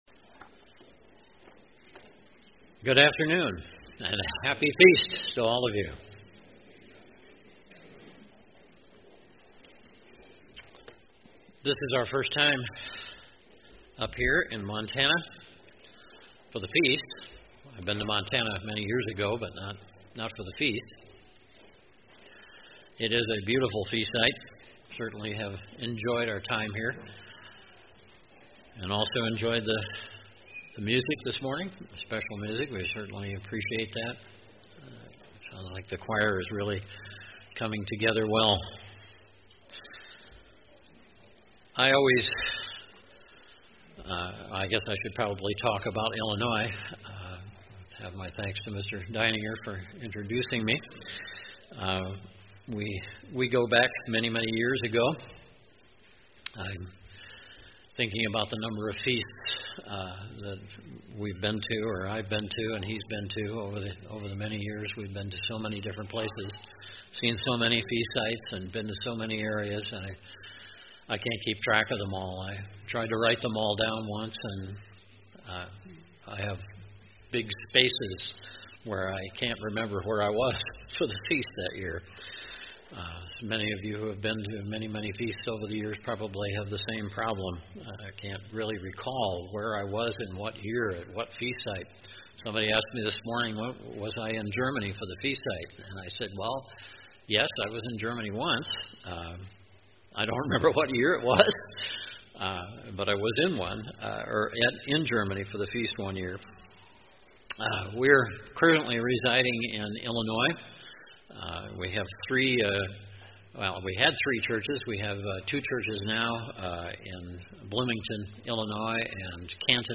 This sermon was given at the Glacier Country, Montana 2018 Feast site.